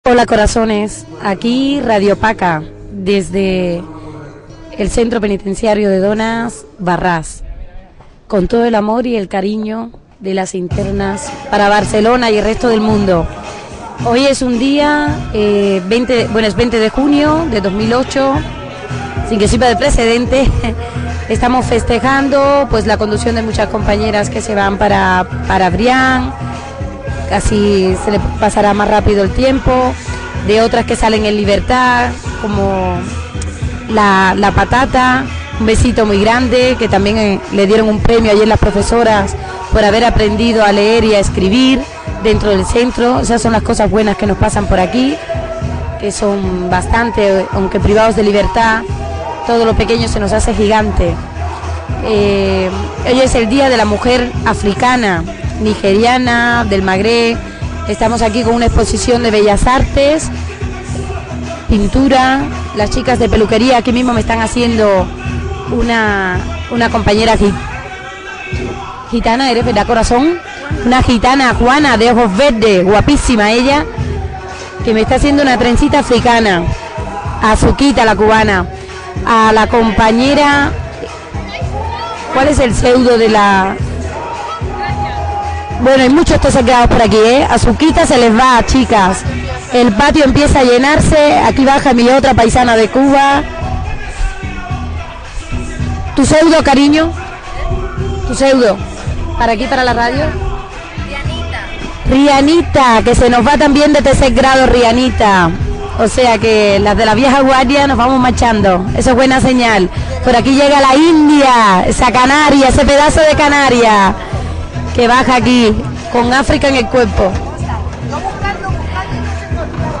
Espai fet des del Centre Penitenciari de Dones Wad-Ras en el dia de la dona africana. Intervencions de diverses internes.
Informatiu
Espai fet per les internes de la presó de dones de Wad-Ras que, a dins de la presó, participaven a Radio Chévere.